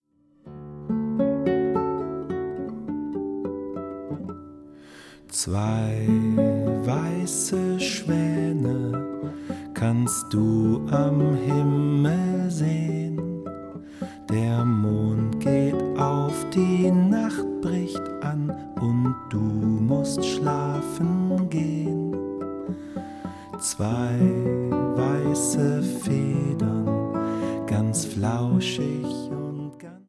Wiegenlieder